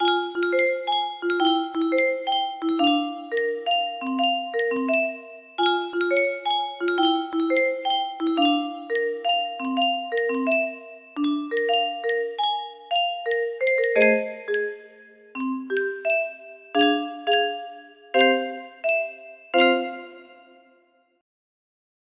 30 box adjusted